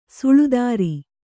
♪ suḷu dāri